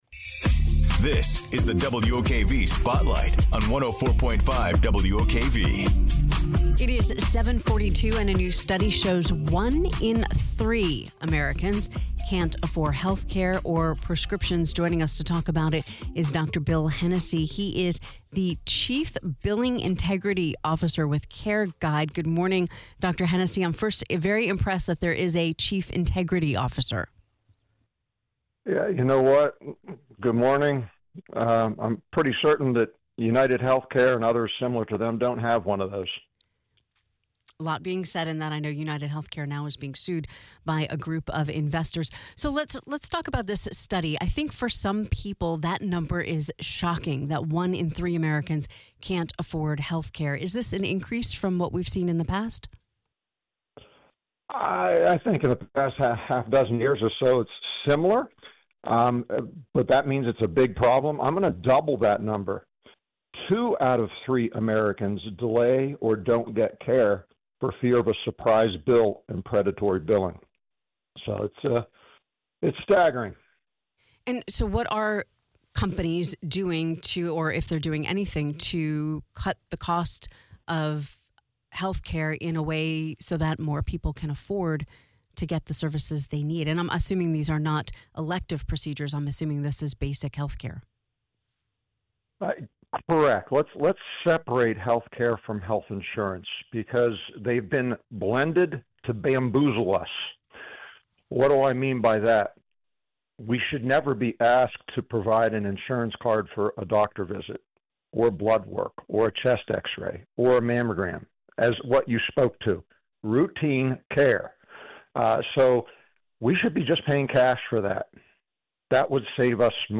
joined Jacksonville’s Morning News with details on a